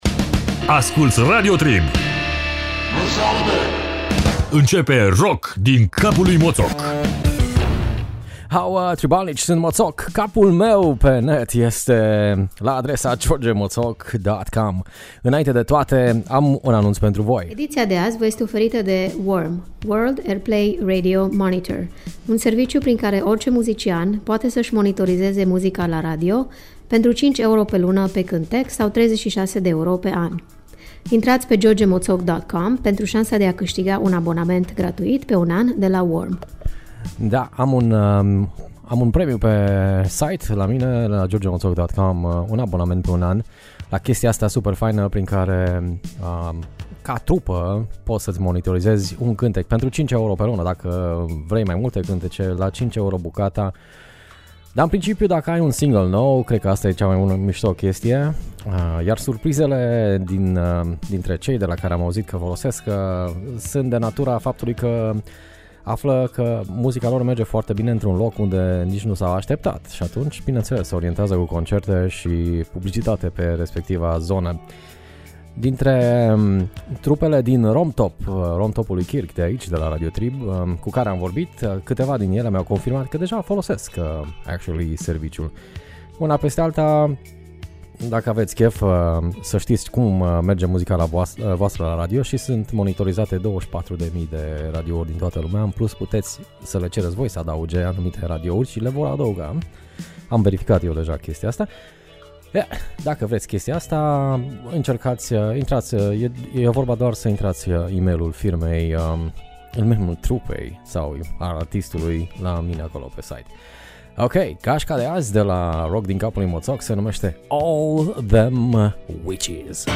Copiii lui Pink Floyd cu mama stoner rock.